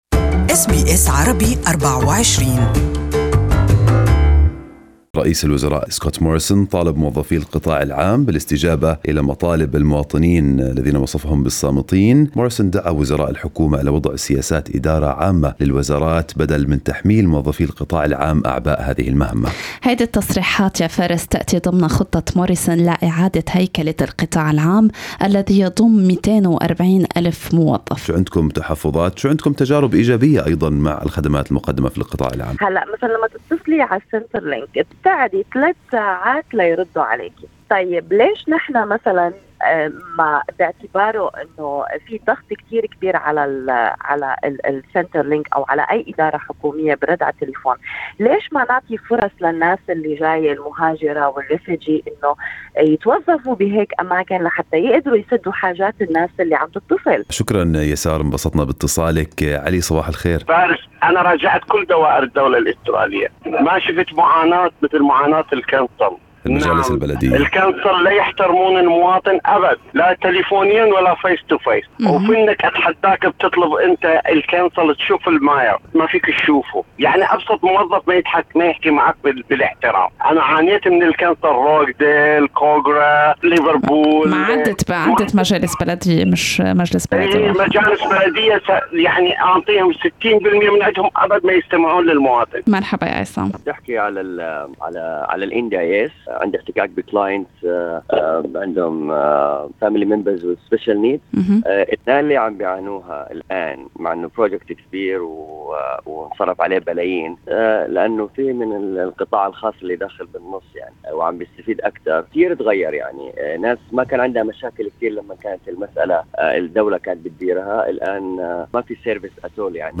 موظف سابق في دائرة الهجرة ومواطنون يقيمون أداء القطاع العام.